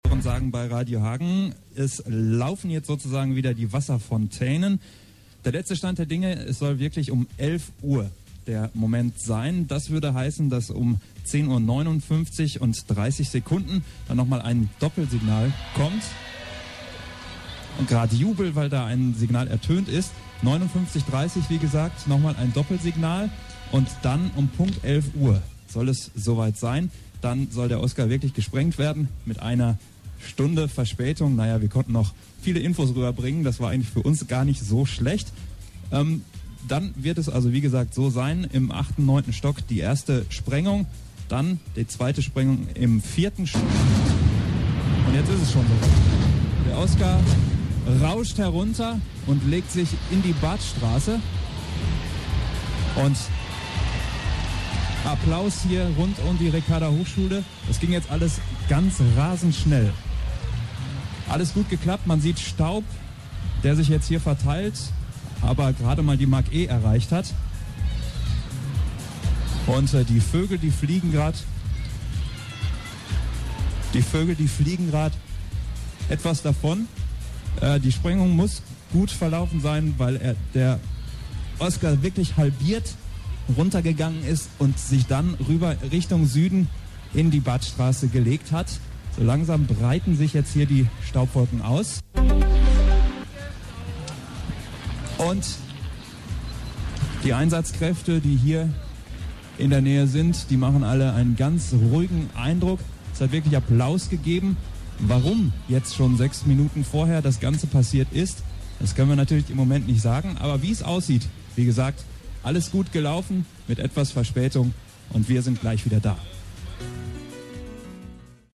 Entsprechend groß damals das Trara zum Abschied - wir erinnern morgen zusammen mit euch!HIER IST DER MOMENT X AUS DER LIVESENDUNG DAMALS!